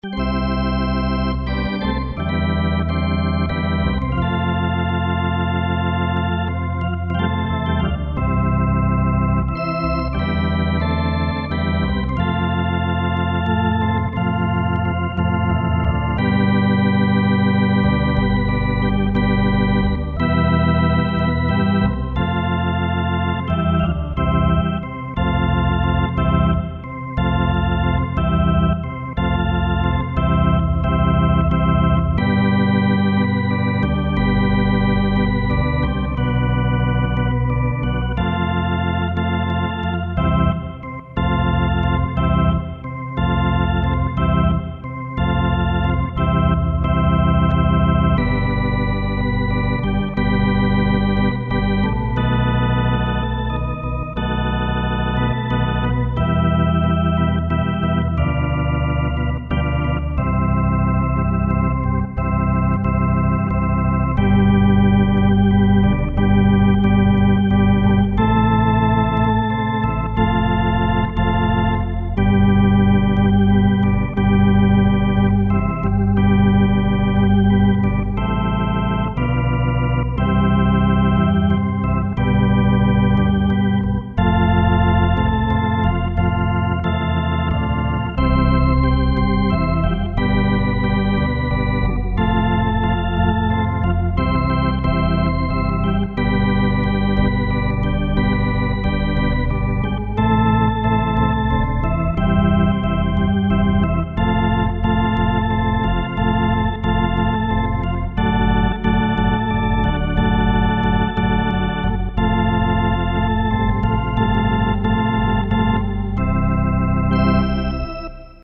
Drawbar Sound Module (1993)
Organ module expander with optional XMC-1 drawbars control.